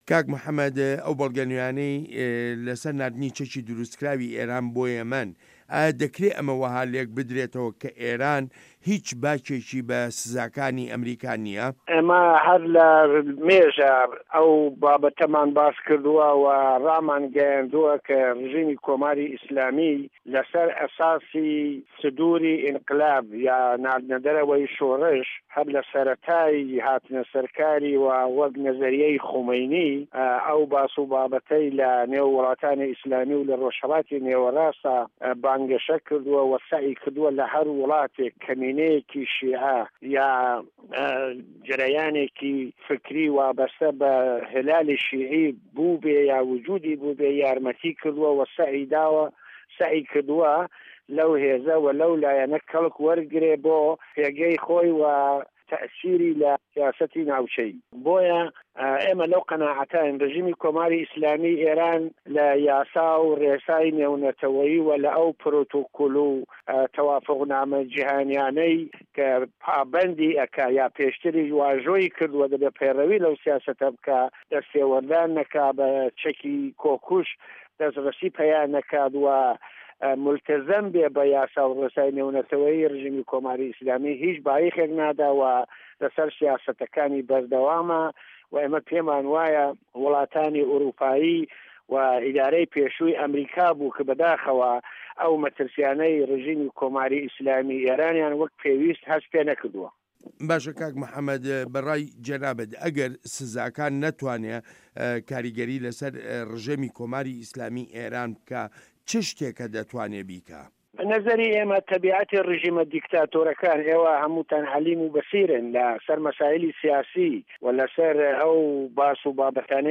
ئێران - گفتوگۆکان